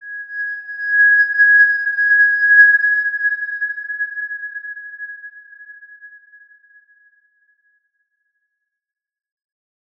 X_Windwistle-G#5-pp.wav